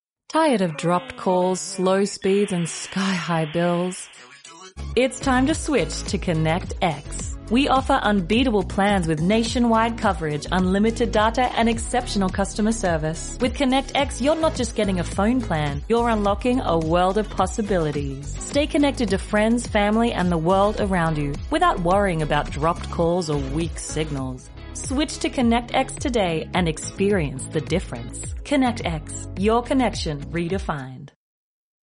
Female
Warm, expressive and naturally engaging, with a clear, bright tone that carries emotional nuance. I specialise in a smooth, conversational delivery that feels authentic and approachable, while still offering the clarity and control needed for professional narration.
Radio Commercials
Aus Accent Phone Company Ad